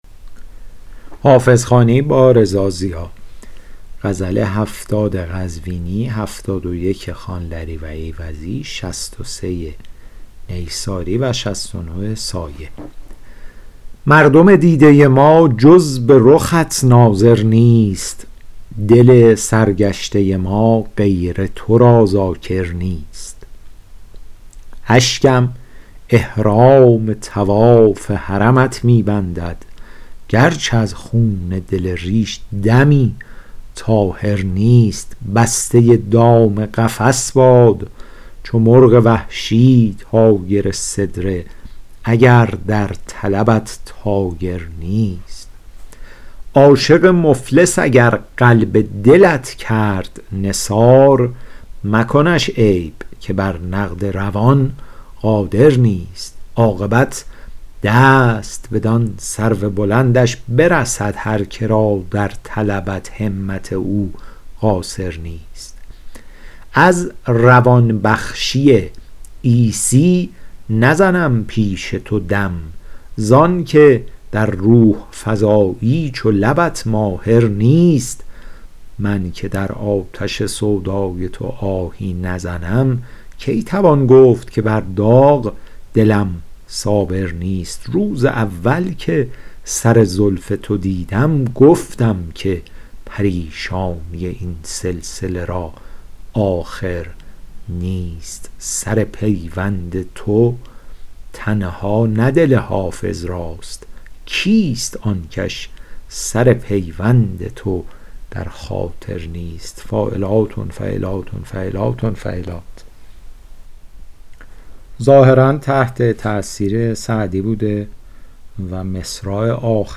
حافظ غزلیات شرح صوتی